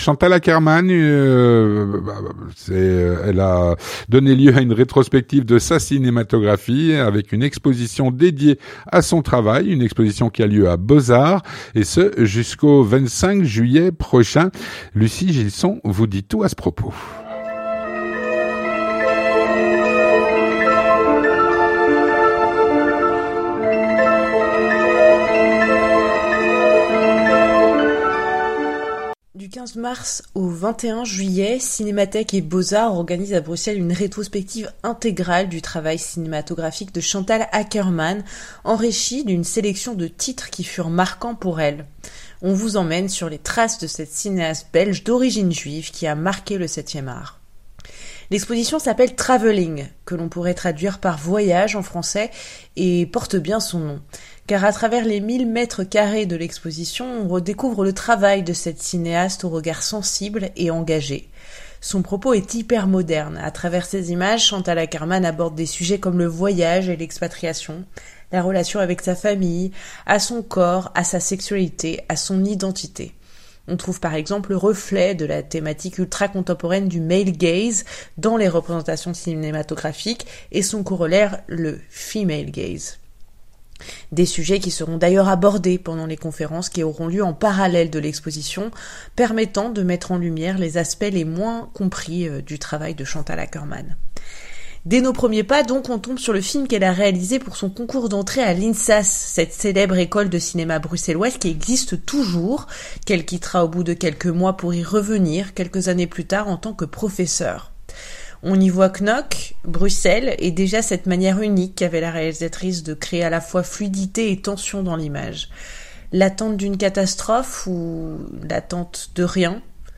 Voici sa chronique.